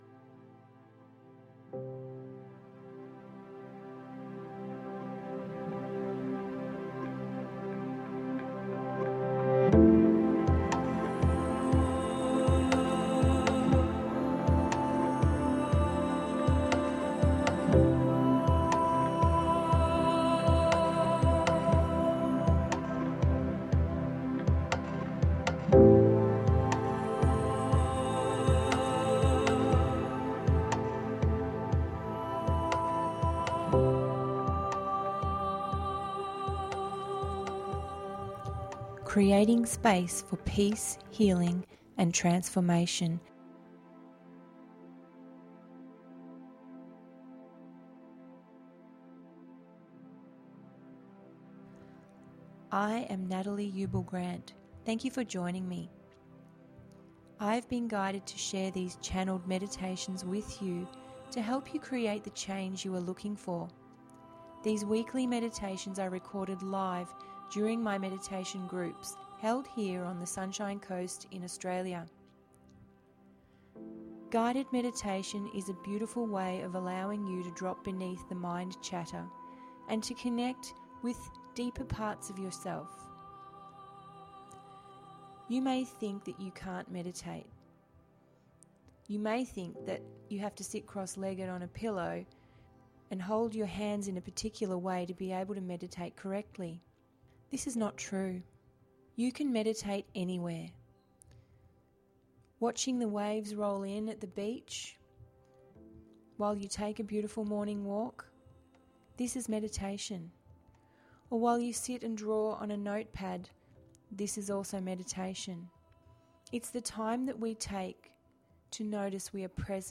100-path-to-peace-guided-meditation-podcast.mp3